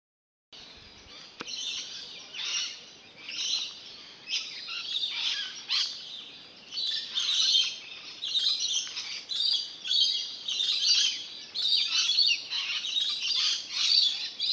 森林里的鸟叫声
描述：森林，鸟叫，清脆
Tag: 自然 鸟叫 清脆 白天 婉转动听